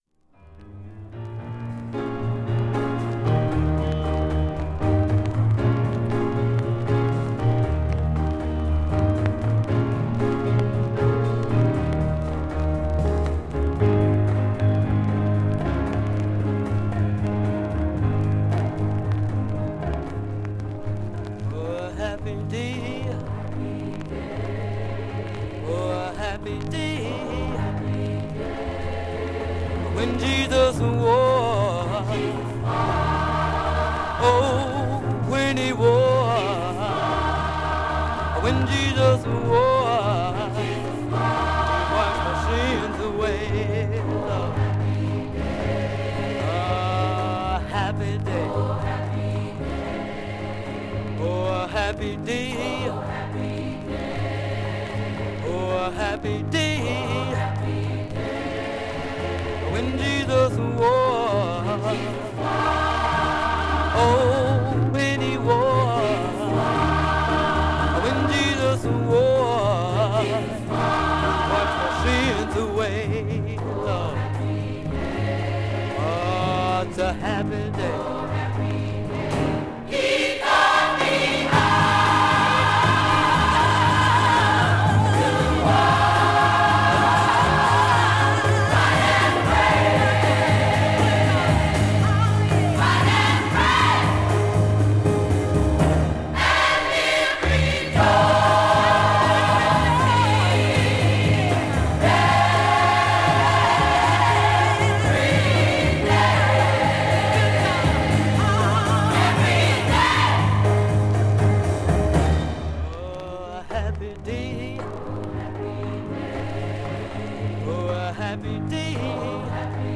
GASPEL